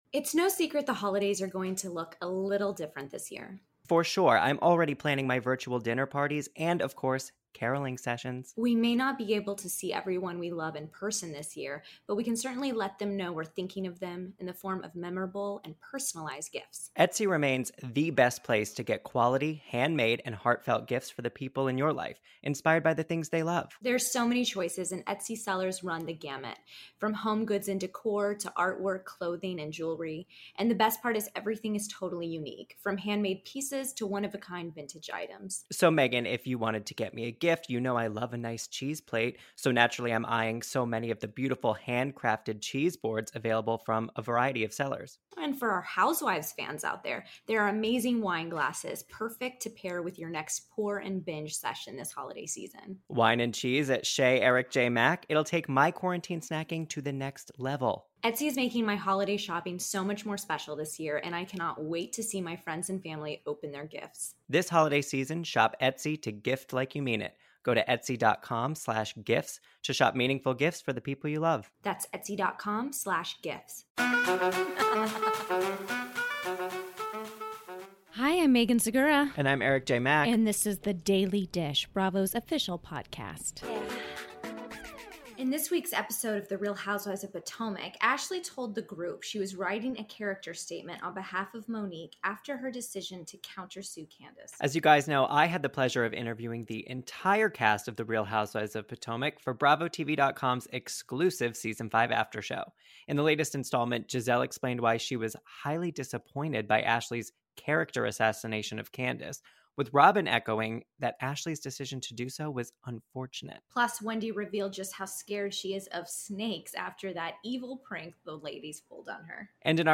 Some of the voices you'll hear beyond the cast are producers - and Daily Dish podcast guests!